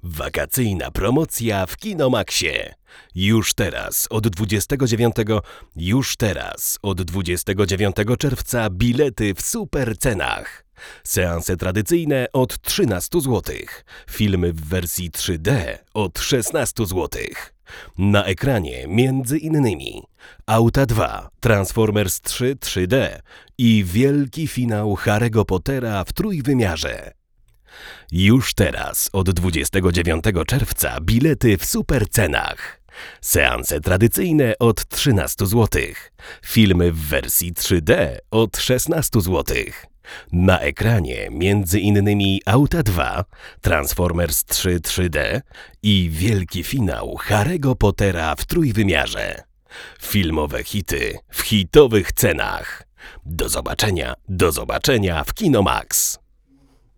Tak jak zawsze, by pominąć różne konfiguracje przedwzmacniaczy testowany mikrofon wpinamy bezpośrednio do interfejsu.
Sygnał jest głośny, czysty i wyraźny. Pierwsze co nam rzuciło się w uszy, to mocno, zdecydowanie zaznaczony charakter brzmienia.
W zamian mamy naprawdę soczyście wyeksponowany dół.
Choć zaznaczyć trzeba, że przejrzystość i selektywność na tym nie ucierpiała.